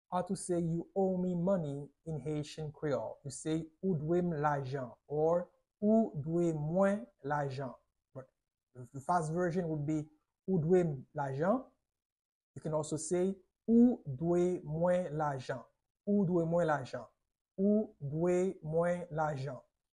How to say "You owe me money" in Haitian Creole - "Ou dwe mwen lajan" pronunciation by a native Haitian Creole teacher
“Ou dwe mwen lajan” Pronunciation in Haitian Creole by a native Haitian can be heard in the audio here or in the video below:
How-to-say-You-owe-me-money-in-Haitian-Creole-Ou-dwe-mwen-lajan-pronunciation-by-a-native-Haitian-Creole-teacher.mp3